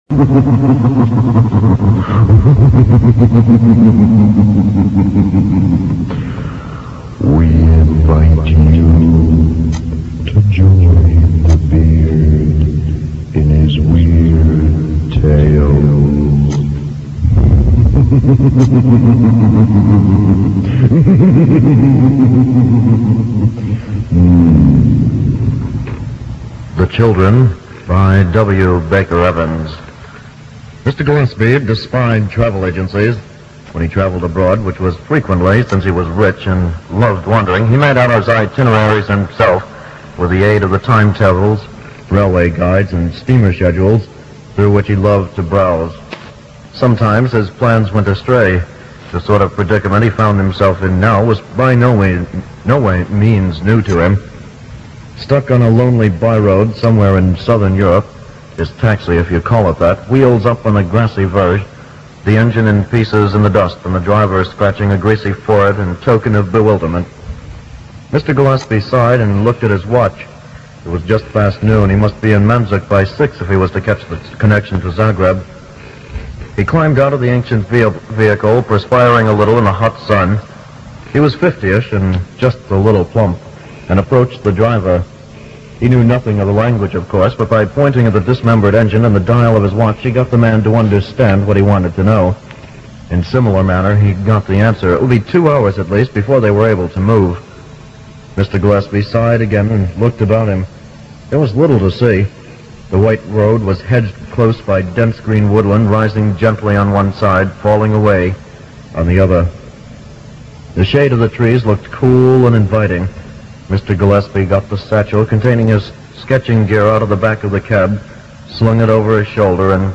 reading one of his “Weird Tales” on Radio Caroline South International on a late night show in 1968